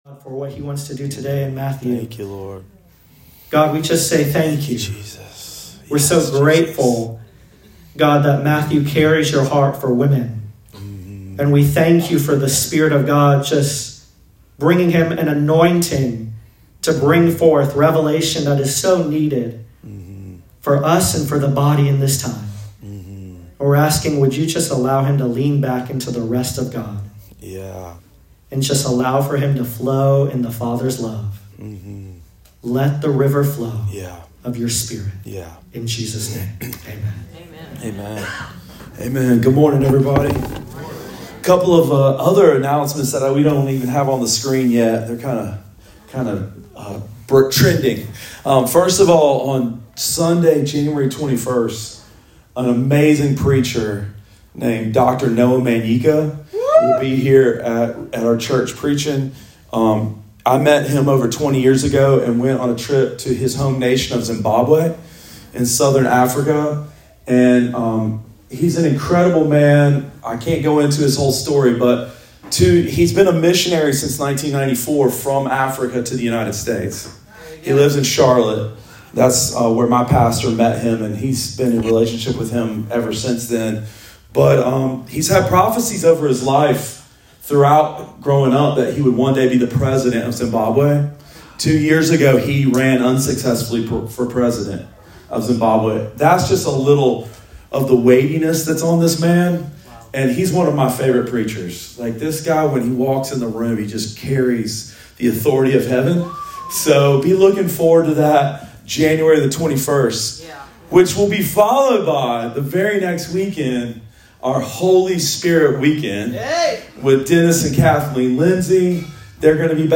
Sermon of the Week: 11-26 – RiverLife Fellowship Church